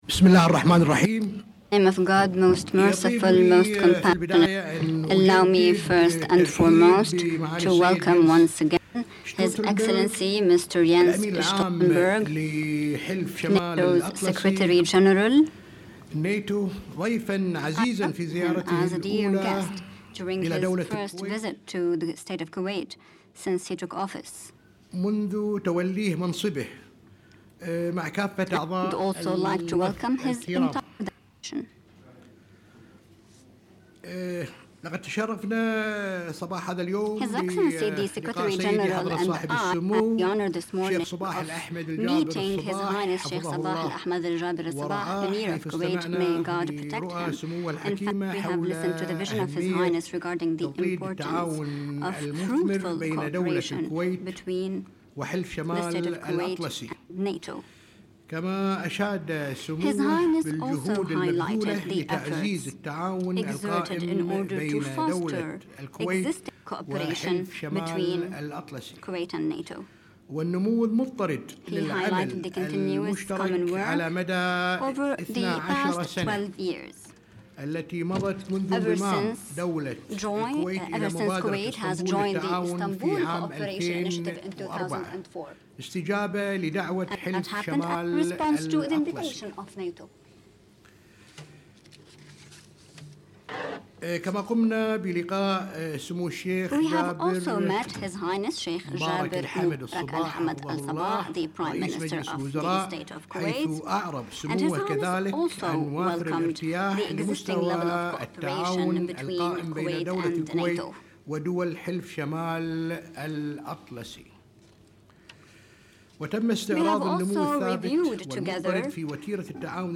Joint press conference by NATO Secretary General Jens Stoltenberg and Sheikh Jaber Mubarak Al-Hamad Al-Sabah, the First Deputy Prime Minister and Minister of Foreign Affairs following the signing of the NATO-Kuwait Transit Agreement 29 Feb. 2016 | download mp3